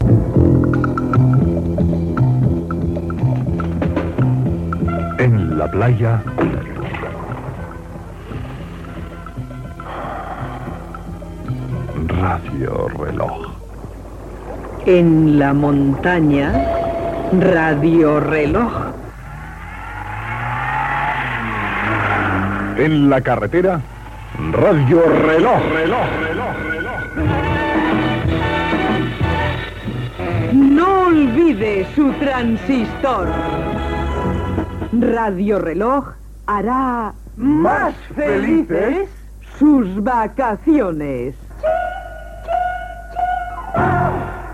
Promo d'estiu de la programació de Radio Reloj.